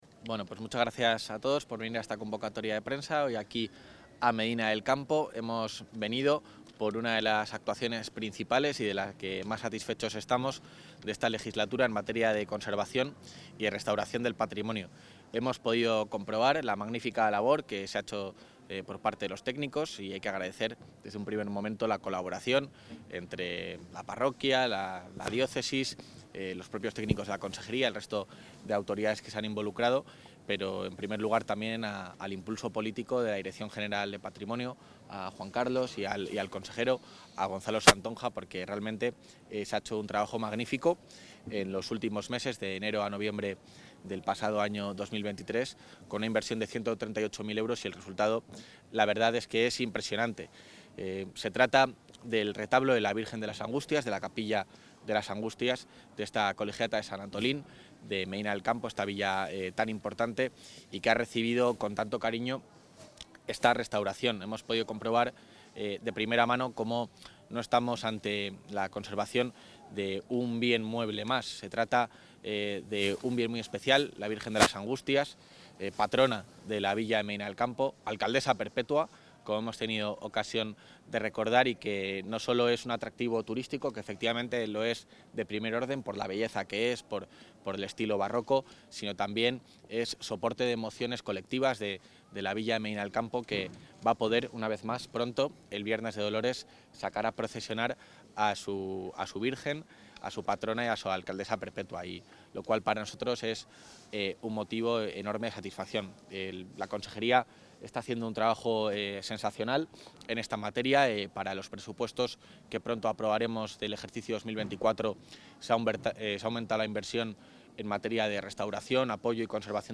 El vicepresidente del Gobierno autonómico ha visitado la rehabilitación del retablo de las Angustias, en la Colegiata de Medina del Campo.
Intervención del vicepresidente de la Junta